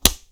Close Combat Character Damage 3.wav